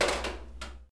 dropball2.wav